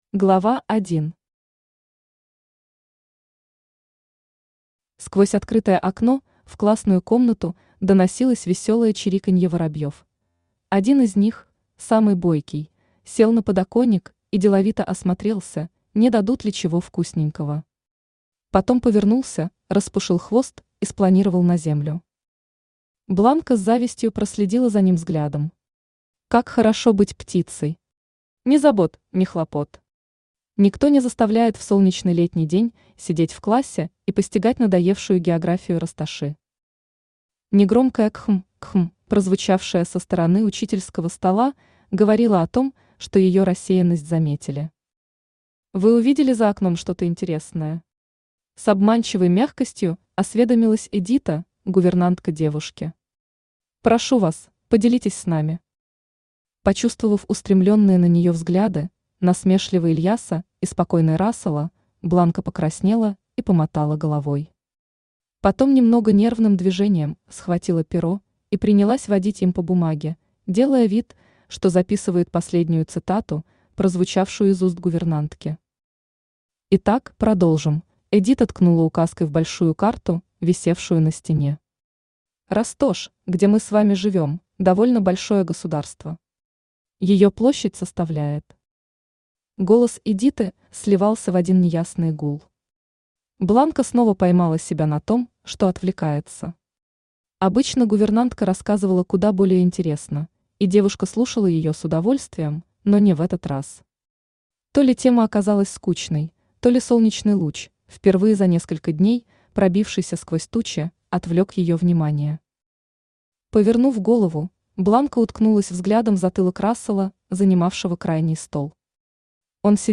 Аудиокнига Леди и оборотень | Библиотека аудиокниг
Aудиокнига Леди и оборотень Автор Татьяна Абиссин Читает аудиокнигу Авточтец ЛитРес.